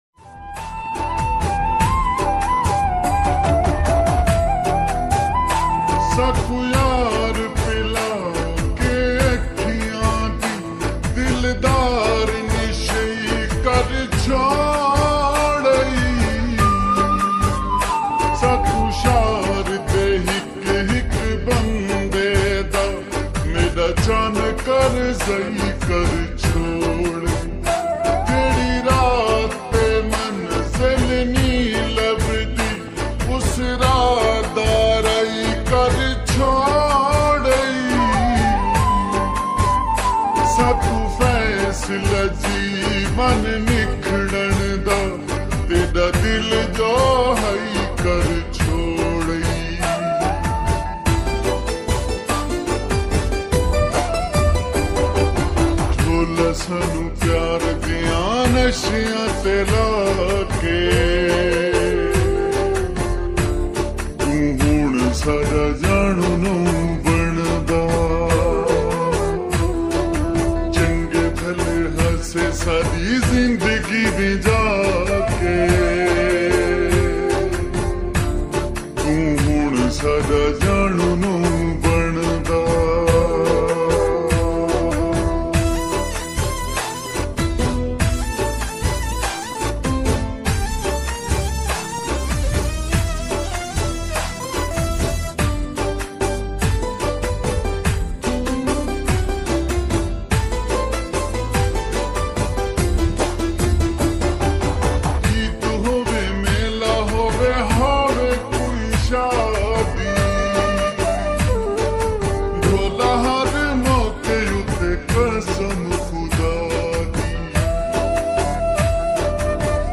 Full Song Slowed And Reverb